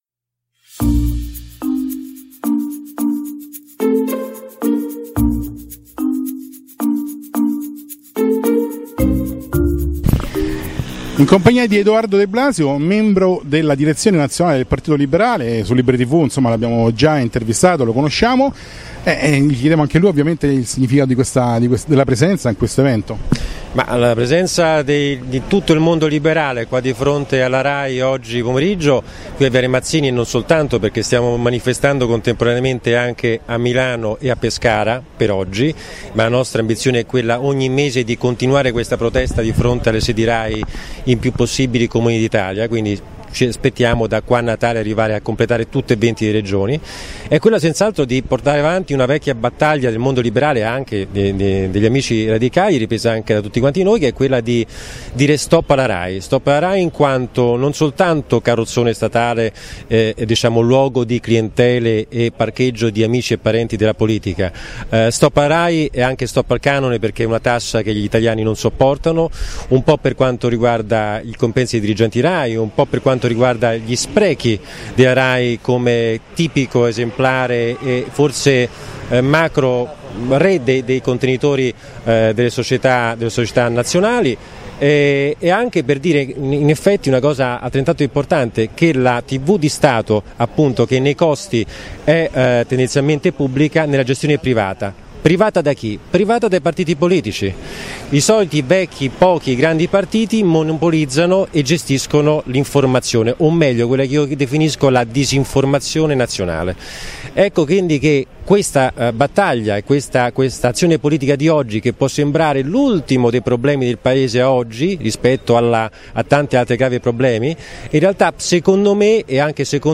Privatizzare la RAI - Manifestazione silenziosa del mondo liberale.